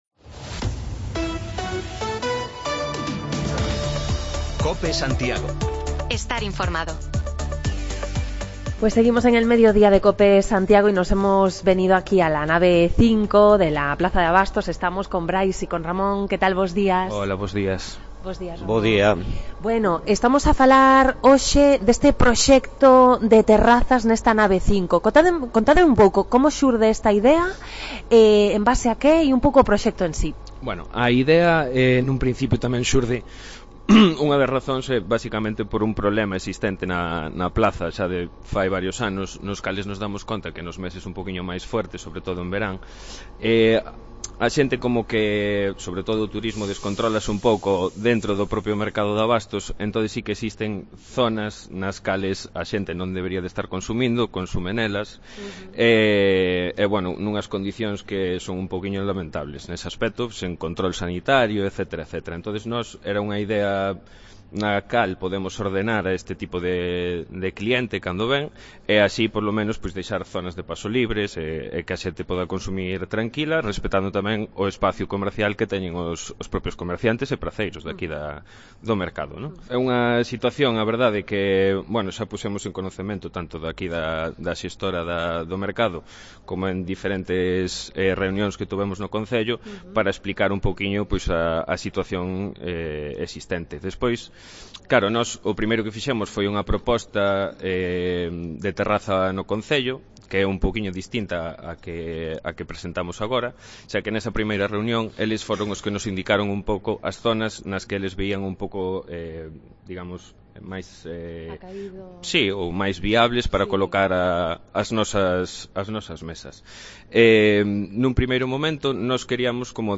Nos acercamos hasta la plaza de Abastos, para conocer más detalles sobre el proyecto de los usuarios de las casillas de la Nave 5, que quieren que se amplíe la zona de terrazas. Además, el alcalde de Ames, Blas García, nos cuenta en Cope que esperan que en abril concluyan las obras de humanización en Bertamiráns y nos habló además del complejo deportivo de Milladoiro